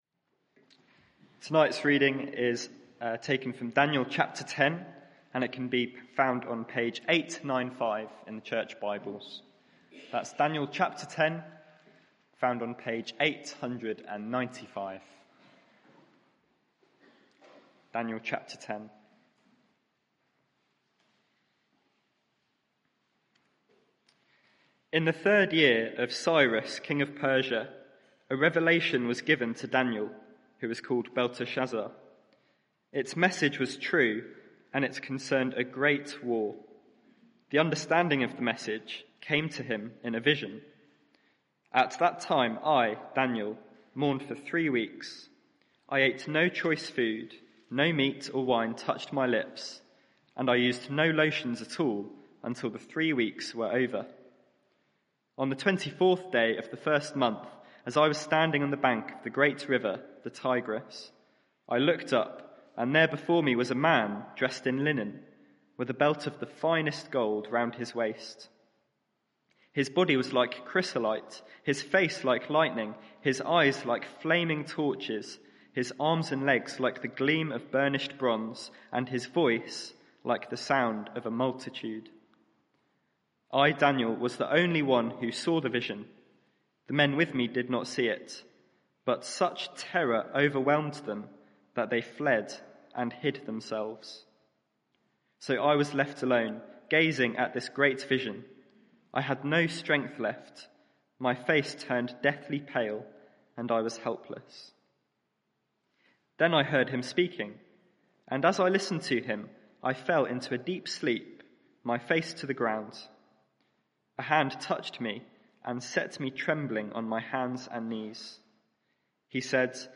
Media for 6:30pm Service on Sun 16th Jul 2023 18:30 Speaker
Daniel 10 Sermon (audio) Search the media library There are recordings here going back several years.